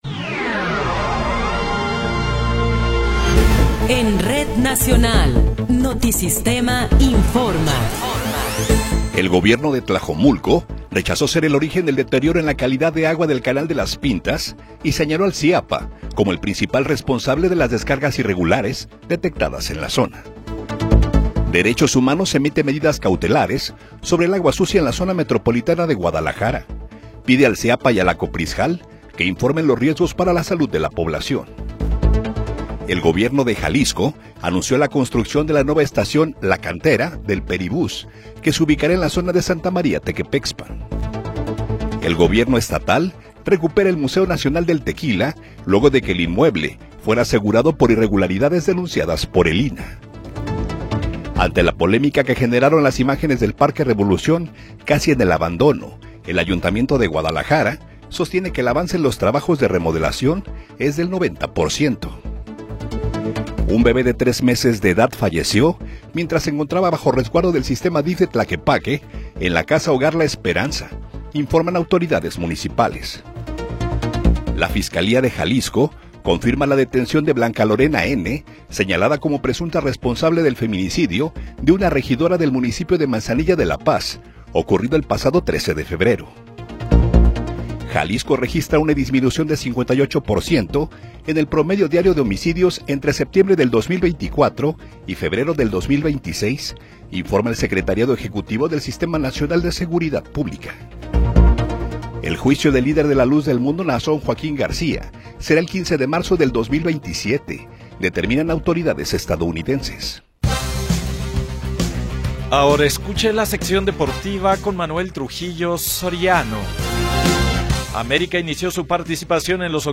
Noticiero 21 hrs. – 10 de Marzo de 2026
Resumen informativo Notisistema, la mejor y más completa información cada hora en la hora.